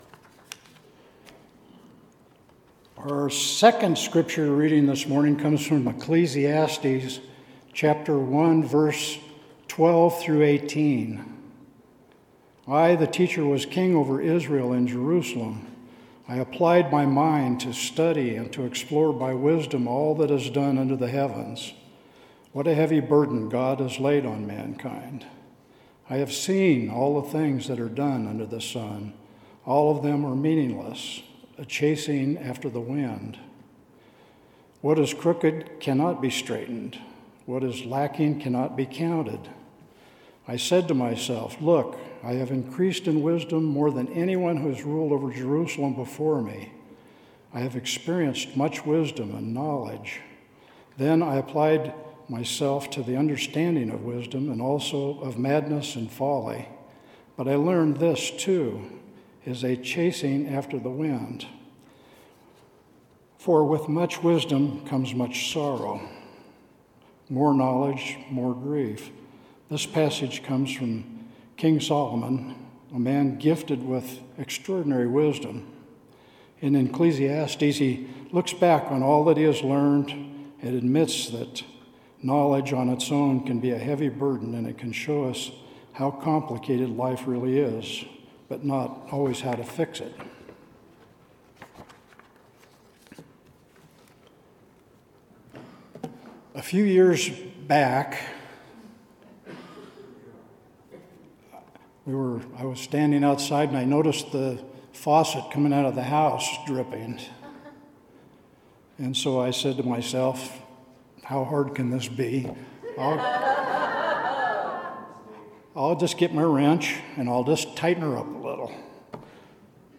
Sermon – July 13, 2025 – “Wisdom Whispers” – First Christian Church